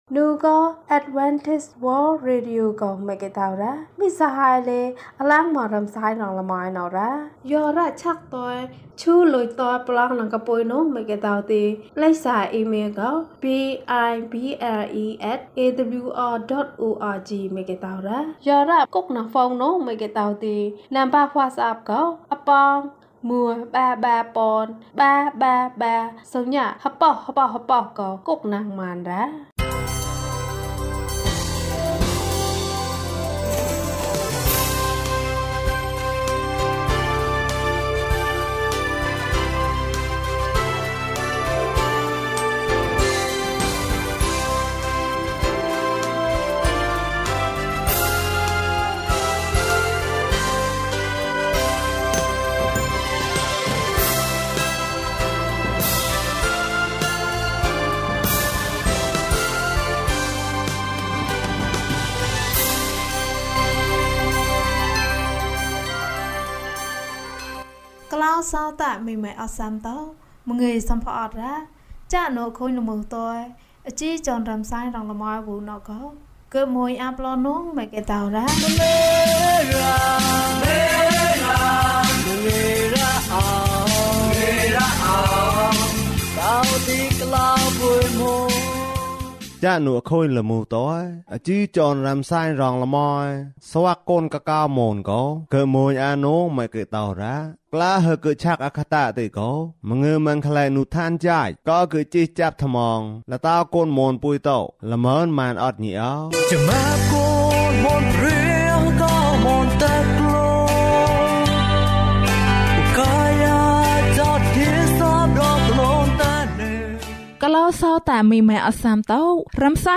ခရစ်တော်ထံသို့ ခြေလှမ်း။၃၉ ကျန်းမာခြင်းအကြောင်းအရာ။ ဓမ္မသီချင်း။ တရားဒေသနာ။